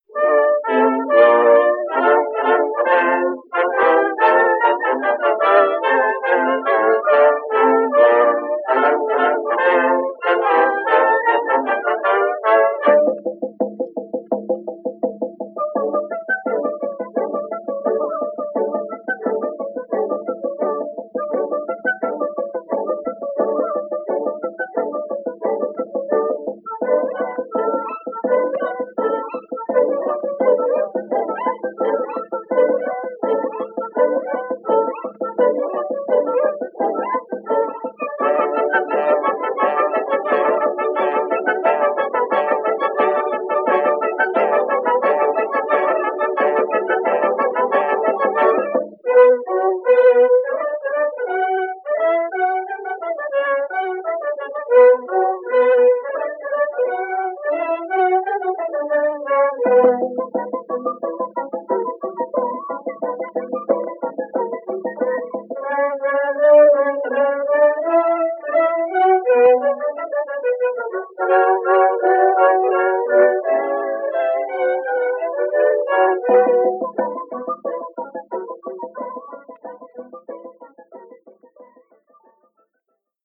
La farandola è una danza popolare francese tipica della Provenza.
Classical-Music-Royalty-Free-1910-Farandole-from-LArlesienne-Bizet-NO-ADS-1.mp3